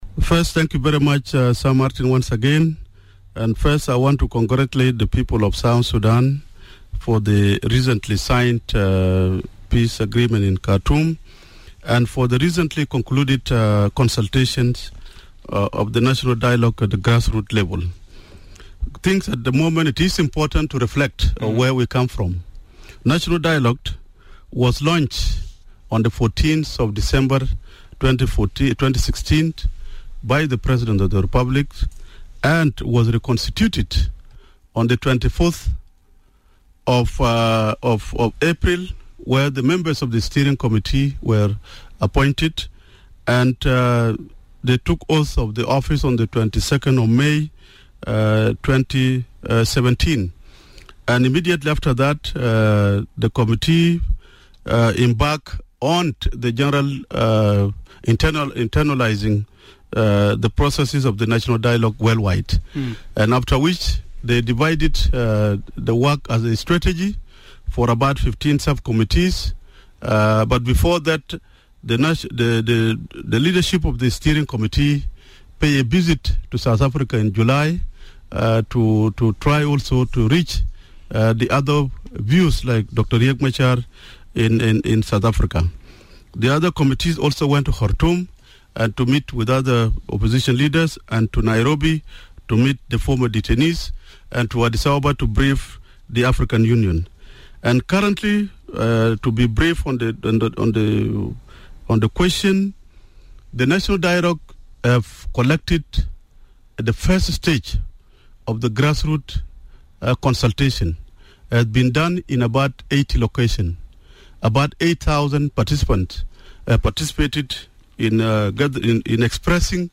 Hon Deng Dau Speaks about progress made on National Dialogue with South Sudanese Refugees
The committee on Refugees and International outreach has held consultations with refugees in Uganda and Ethiopia and Hon. Deng Dau was hosted on Democracy in Action Program, to talk about the notable progress, challenges and milestones achieved in their dialogue with the South Sudanese refugee community.